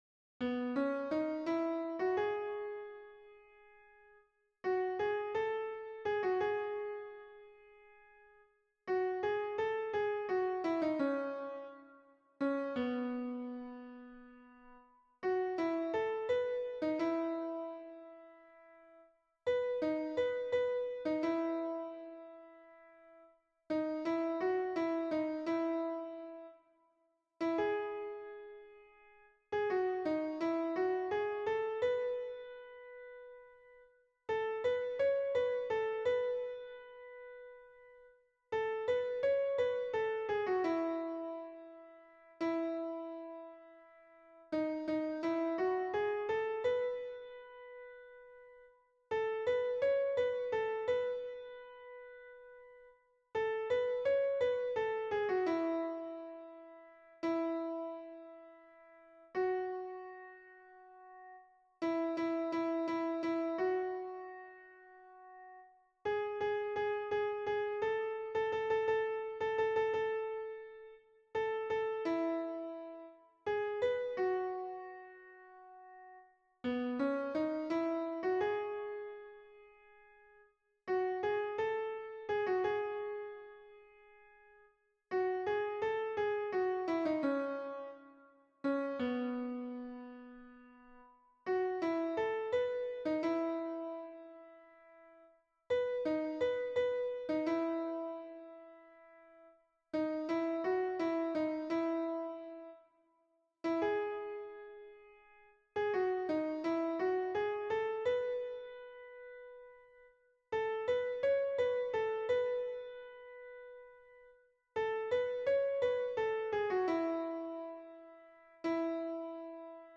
MP3 version piano
Ténor